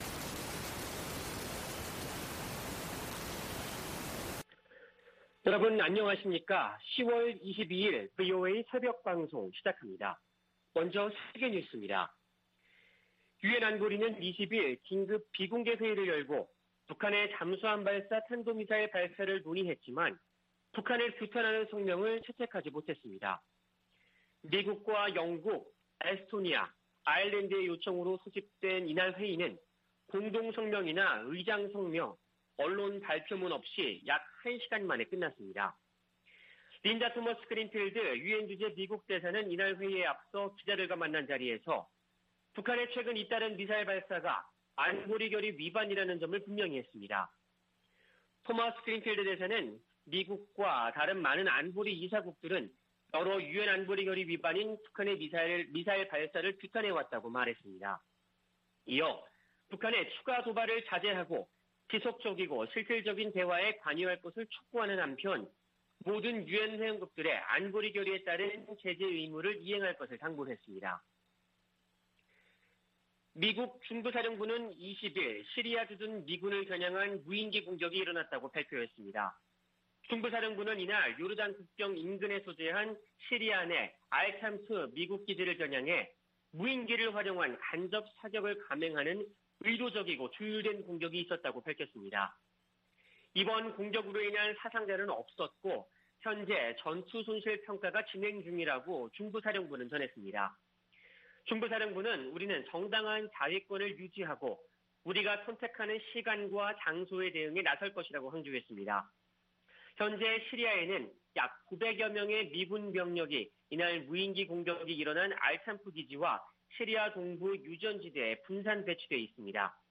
VOA 한국어 '출발 뉴스 쇼', 2021년 10월 22일 방송입니다. 유엔 안보리가 북한 SLBM 규탄 성명을 내는데 실패했습니다. 독일과 영국이 북한의 미사일 시험 발사를 규탄하며, 완전하고 검증 가능하며 되돌릴 수 없는 핵포기를 촉구했습니다. 북한은 SLBM 시험발사가 미국을 겨냥한 게 아니라고 주장했습니다.